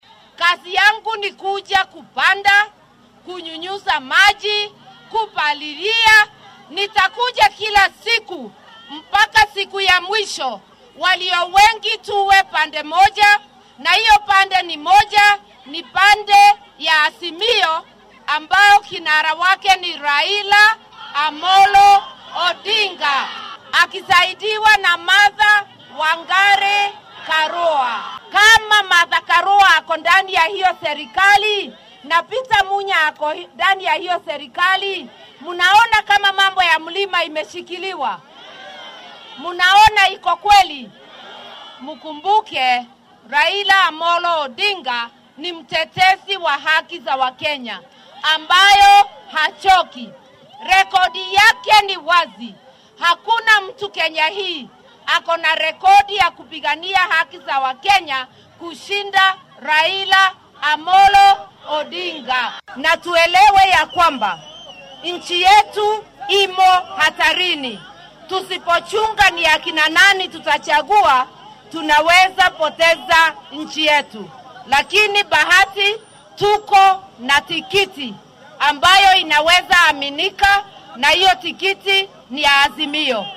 Xilli ay shalay ku sugnayd ismaamulka Kirinyaga oo ay dhalasho ahaan ka soo jeedo ayay arrintan sheegtay. Waxay hoosta ka xarriiqday in diiradda saari doonto dhaqangelinta ballanqaadyada horumarineed ee ay Kenyaanka u sameynayaan iyada iyo Raila.